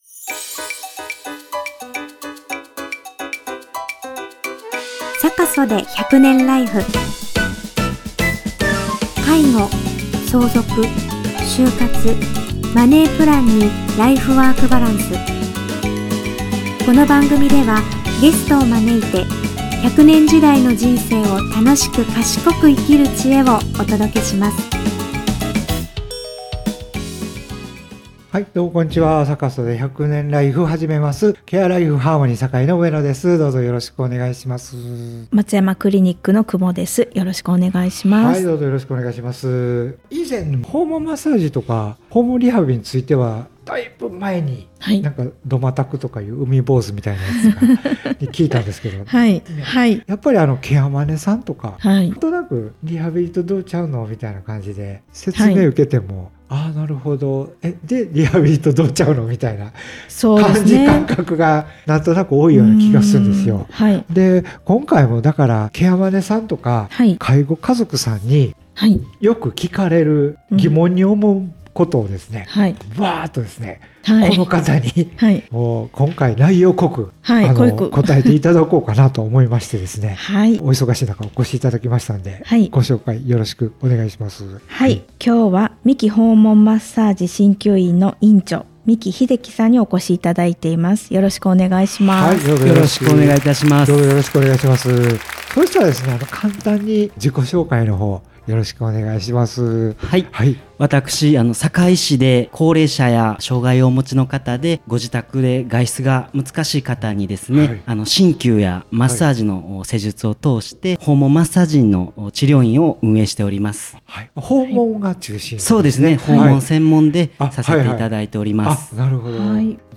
収録：ライフハーモニー介護スクール（堺市北区）